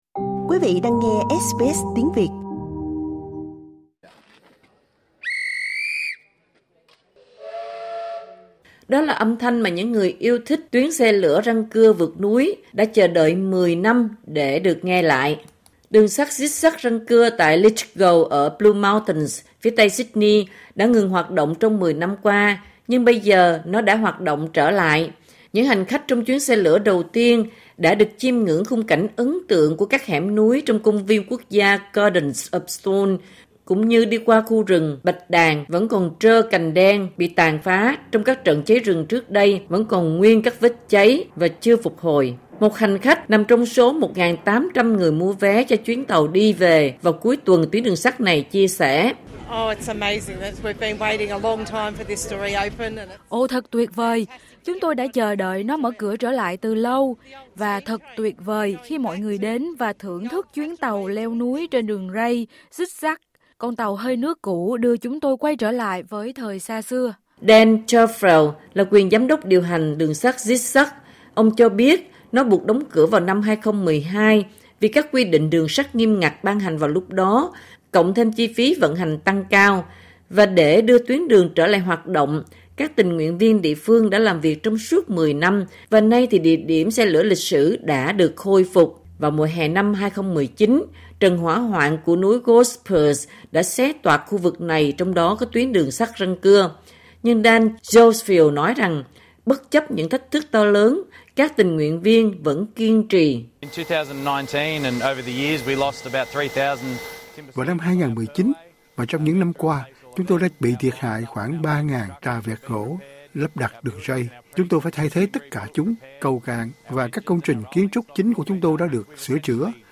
Đó là âm thanh mà những người yêu thích xe lửa răng cưa vượt núi đã chờ đợi mười năm - để được nghe lại.